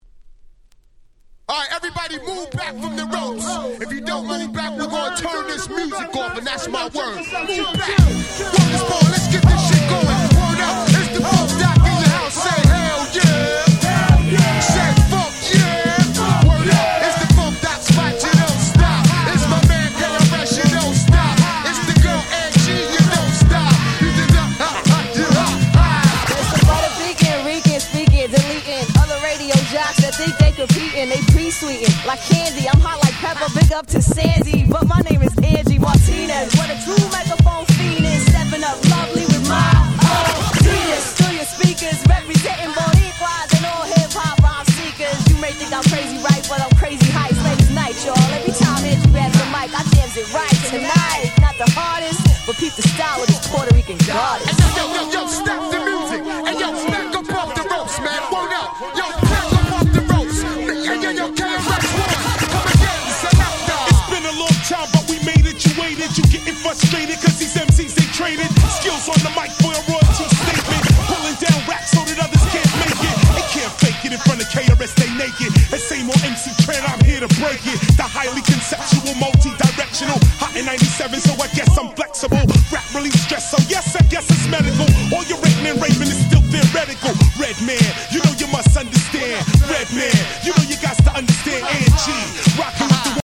※試聴ファイルは一部別の盤から録音してございます。
97' Very Nice Hip Hop !!
ケアレスワン 90's Boom Bap ブーンバップ